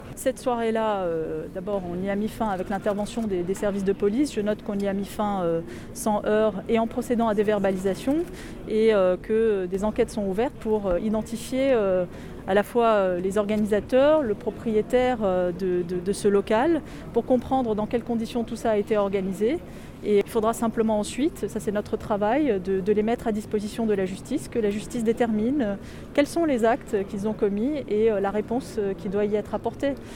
A l’issue d’une cérémonie de prise de fonction dans les formes, avec un dépôt de gerbe au monument des policiers morts du département des Bouches-du-Rhône, un passage en revue des troupes, discours… la nouvelle préfète de police des Bouches du Rhône a rencontré la presse.